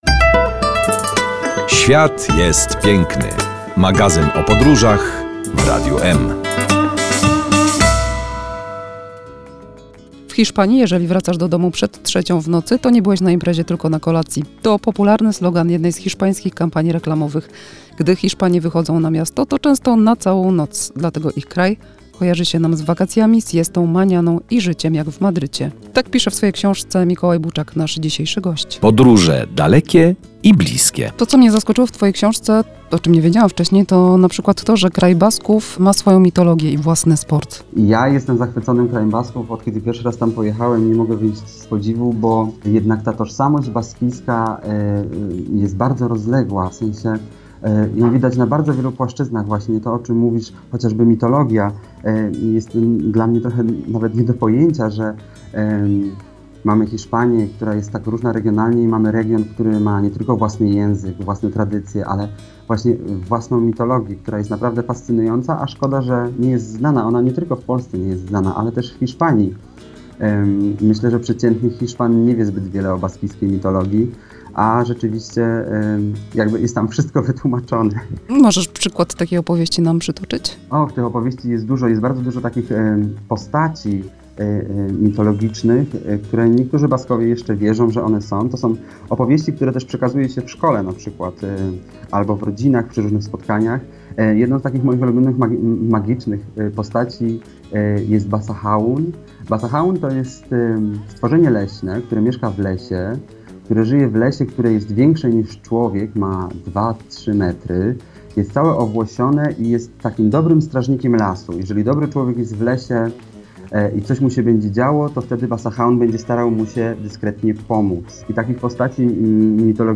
O tym rozmawiamy z podróżnikiem i miłośnikiem Hiszpanii